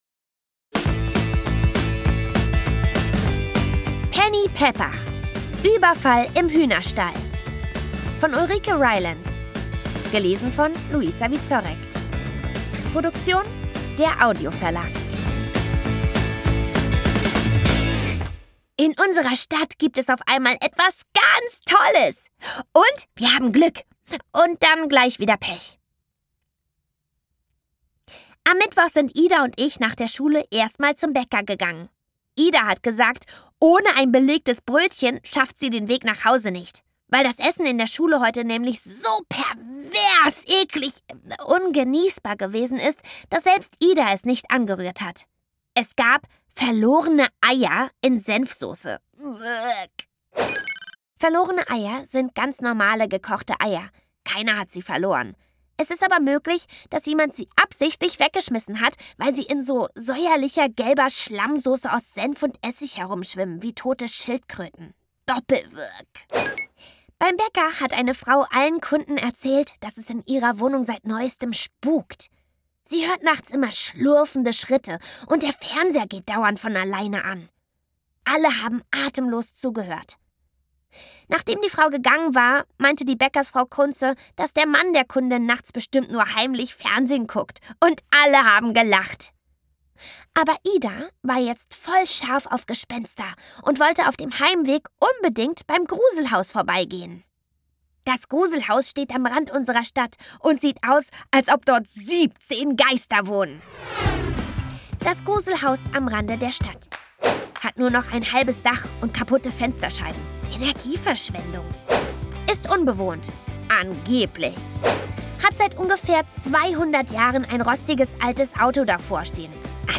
Schlagworte Bauernhof • Comicroman • Comic-Roman ab 8 • Detektiv-Bande • Detektivgeschichte • Detektivin • drei Ausrufezeichen • Drei Fragezeichen Kids • Freundschaft • Hörbuch • Humor • Hund • Kinder ab 8 • Kinderkrimi • Listen • Lotta-Leben • Mädchenbande • Mädchenkrimi • Neuerscheinung 2023 • Penny Pepper • Schule • szenische Lesung mit Musik • Teil 11 • Tierkinder • Ungekürzt • witzig